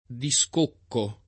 discocco [ di S k 0 kko o di S k 1 kko ], -chi